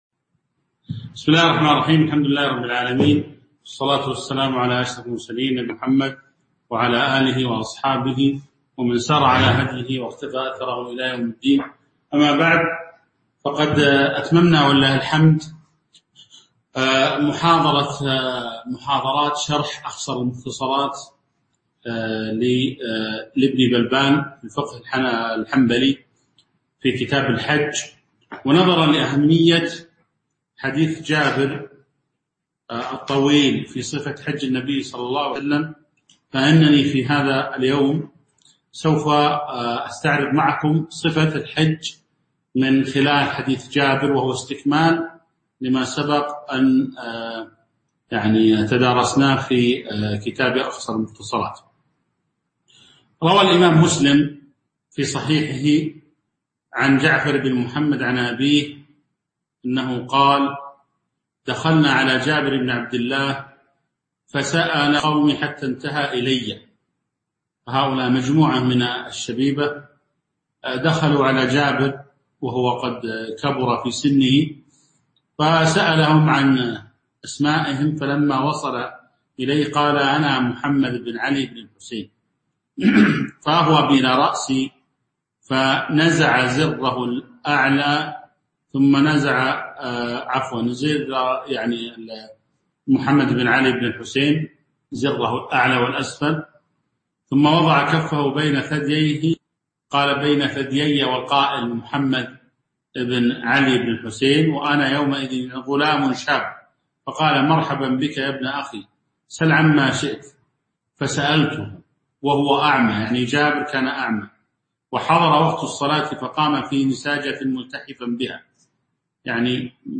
تاريخ النشر ٢٦ شوال ١٤٤١ هـ المكان: المسجد النبوي الشيخ